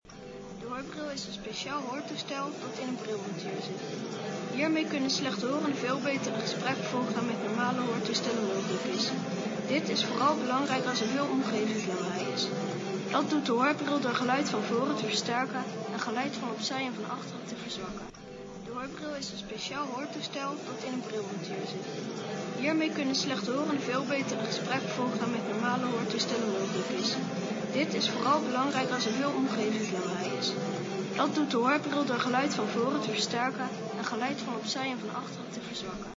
In dit fragment kunt u luisteren naar wat een slechthorende met de Varibel hoorbril op een feestje hoort.
dit-hoort-een-slechthorende-met-de-Varibel-hoorbril-op-een-feestje.mp3